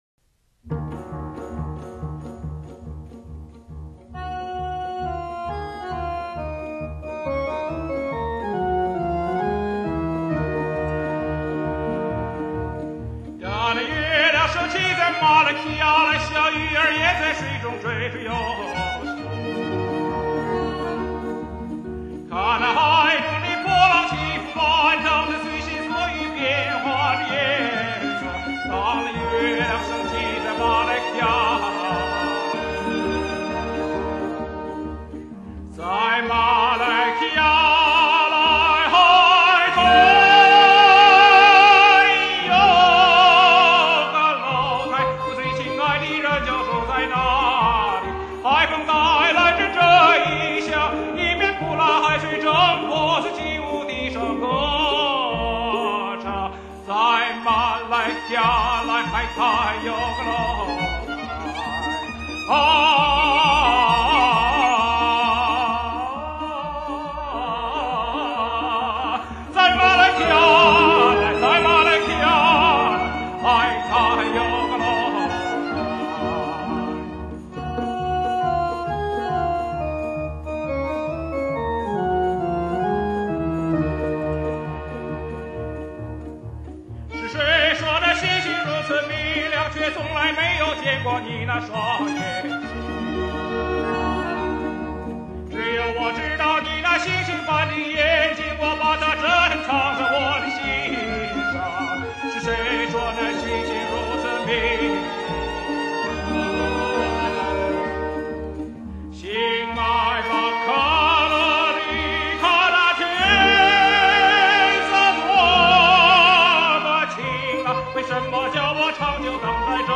历历岁月，世纪回望，世界民歌，乐韵悠长。